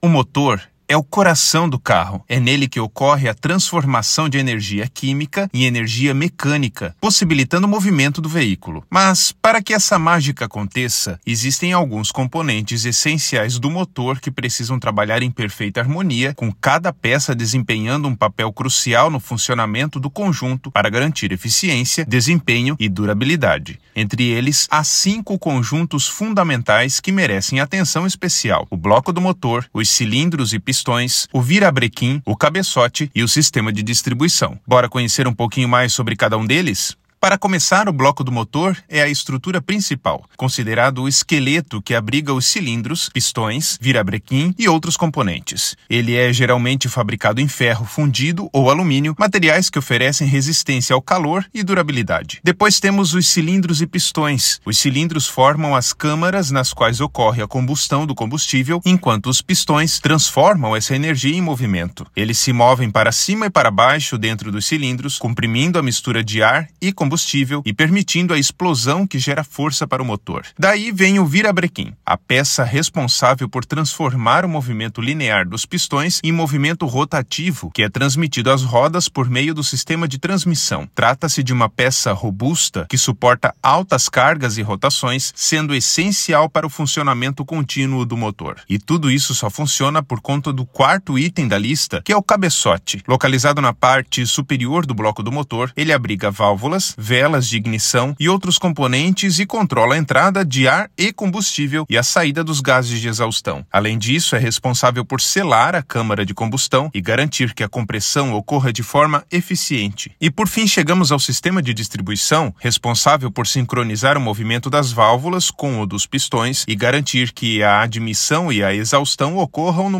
Narracao-01-componentes-essenciais-do-motor.mp3